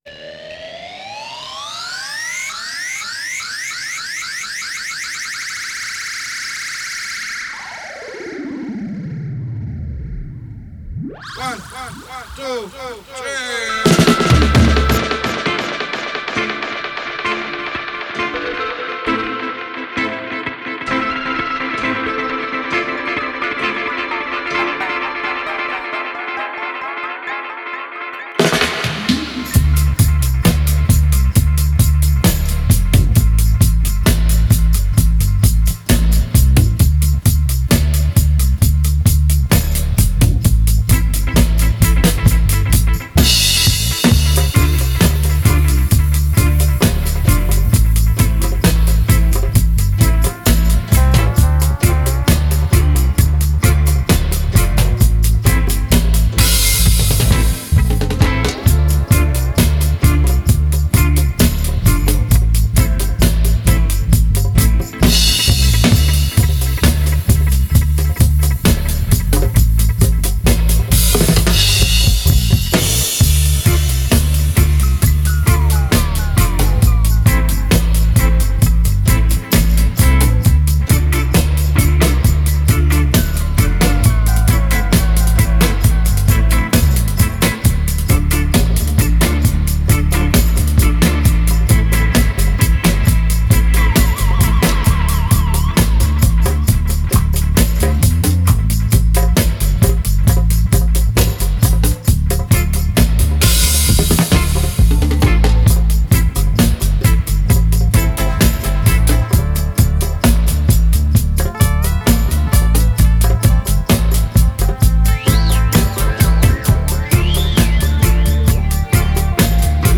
Genre: Reggae, Dub.